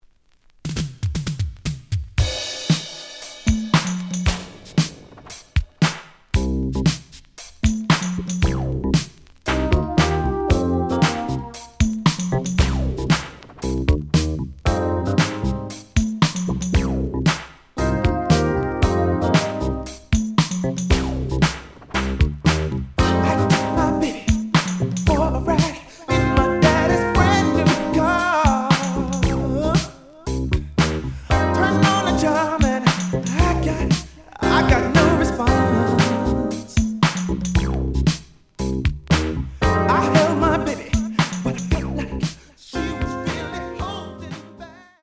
The audio sample is recorded from the actual item.
●Genre: Soul, 80's / 90's Soul
Slight damage on both side labels. Plays good.)